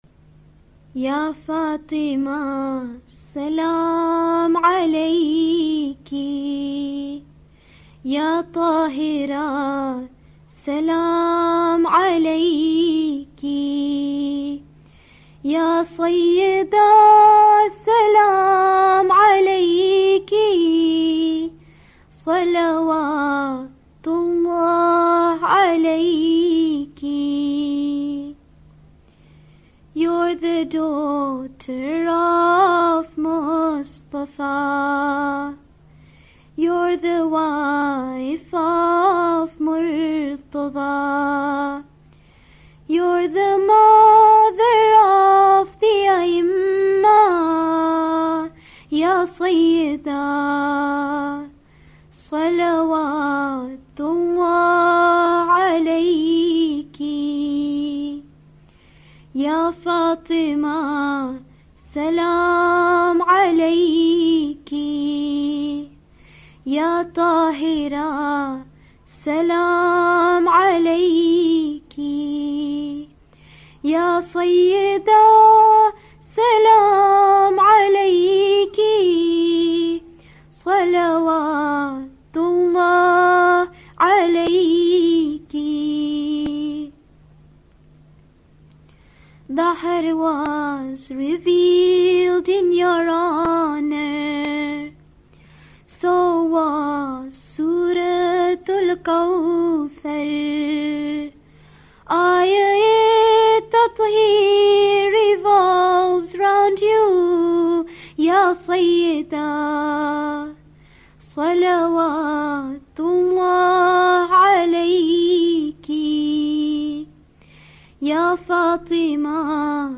Part of a series of Muslim rhymes for children.